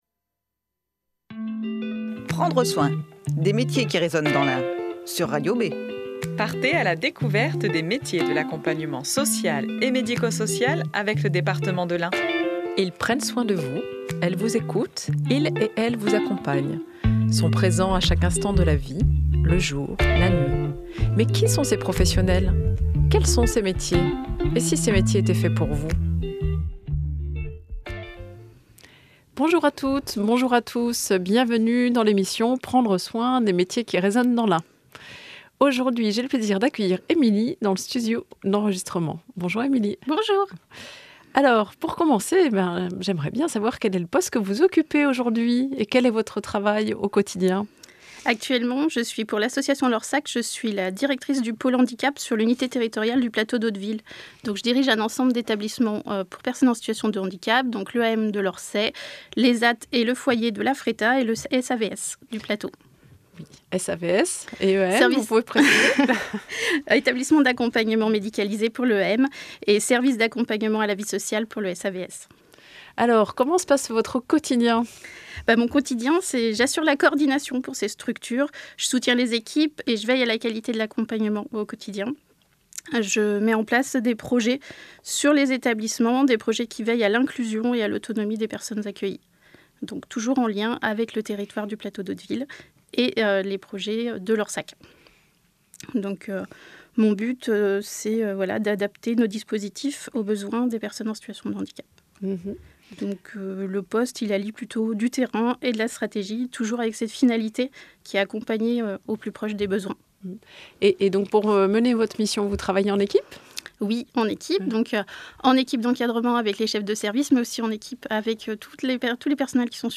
Tous les troisièmes vendredis du mois, retrouvez une "interview minute" avec un professionnel des métiers de l'accompagnement social et médico-social.